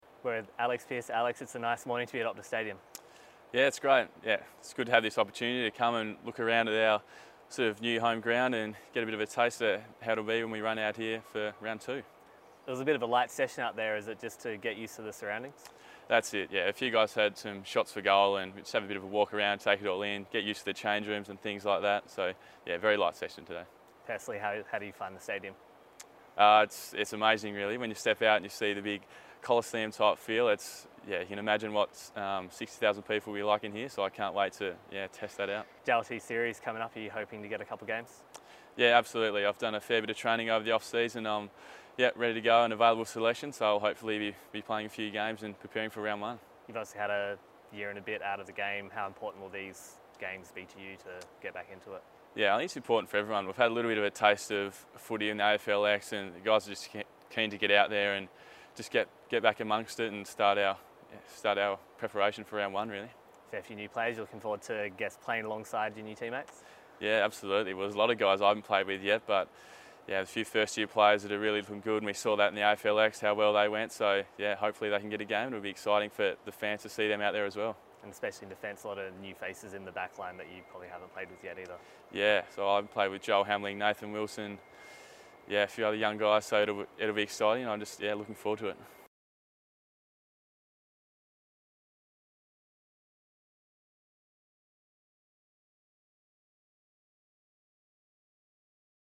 Alex Pearce chats to Docker TV after being named in the JLT Community Series squad.